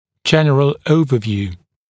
[‘ʤenrəl ‘əuvəvjuː][‘джэнрэл ‘оувэвйу:]общее обзор, общий осмотр